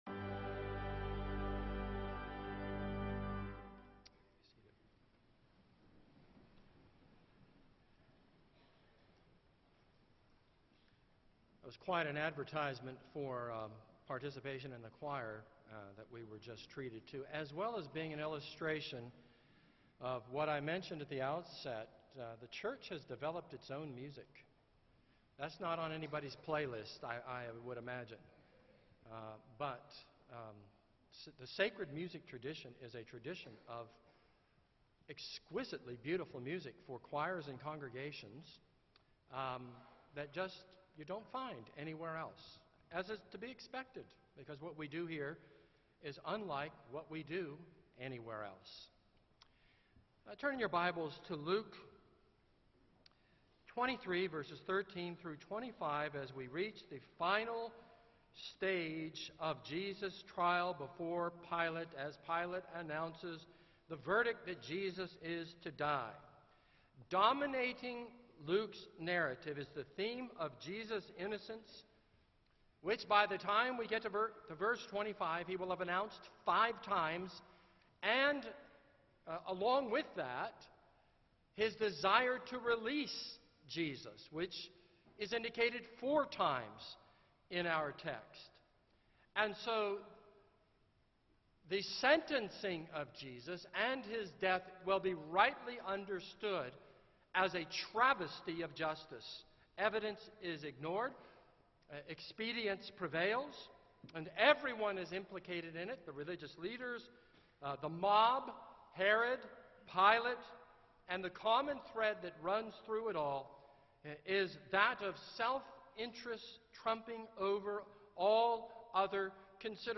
This is a sermon on Luke 23:13-25.